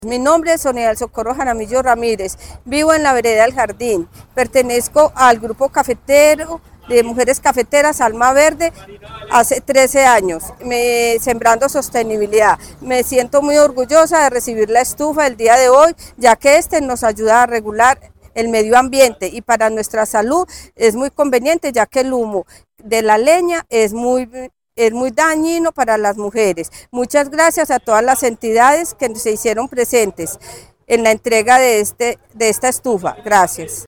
Estufas-beneficiaria.mp3